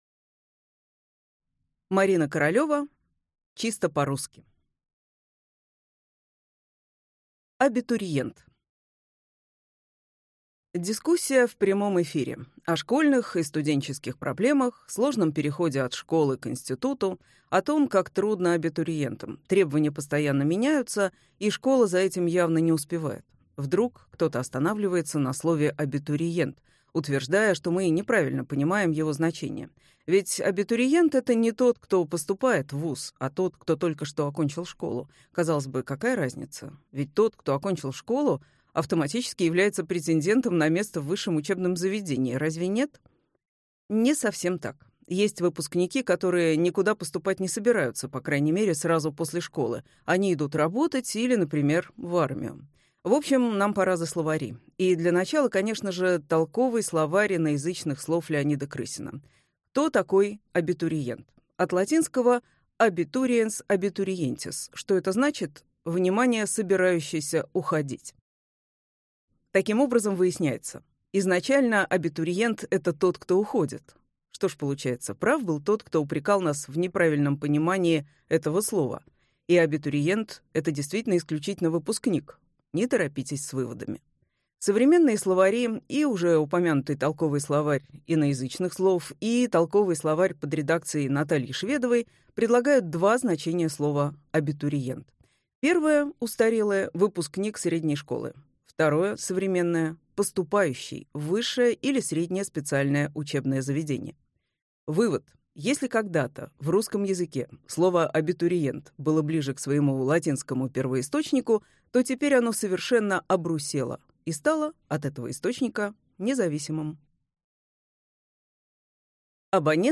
Аудиокнига Чисто по-русски. Говорим и пишем без ошибок | Библиотека аудиокниг